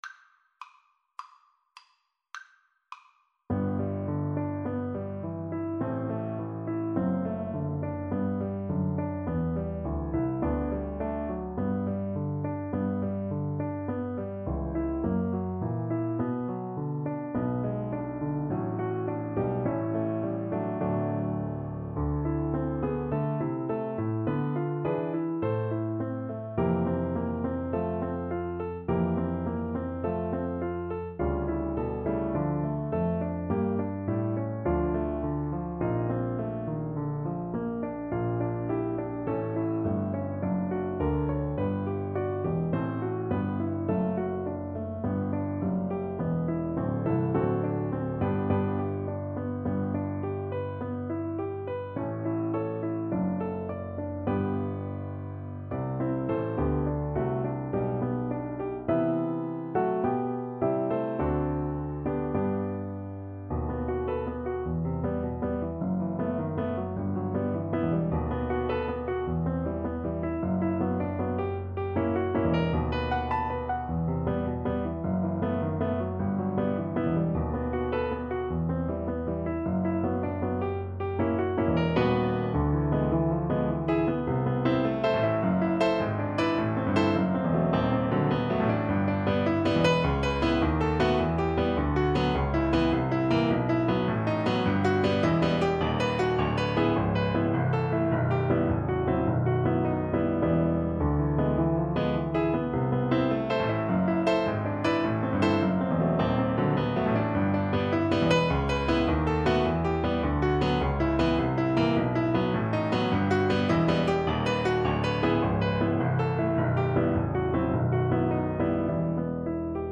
Einfach, innig =104
Classical (View more Classical Cello Music)